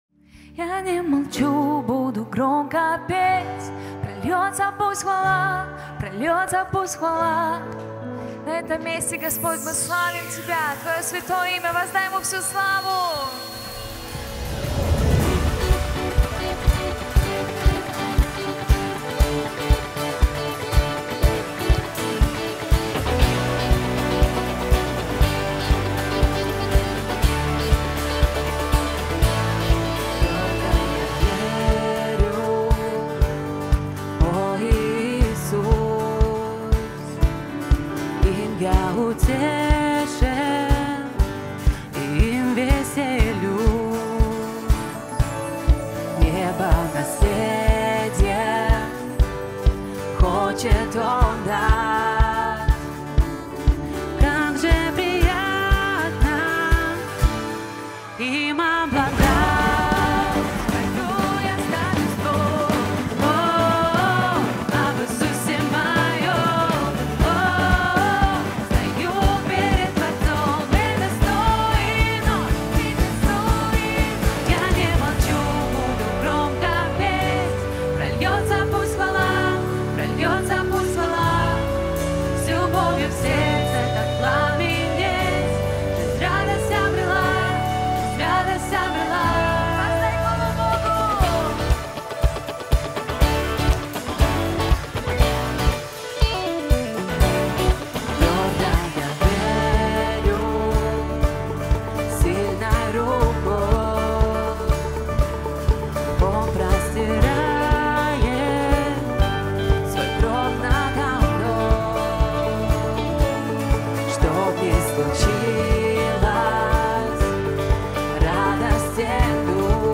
песня
565 просмотров 27 прослушиваний 0 скачиваний BPM: 120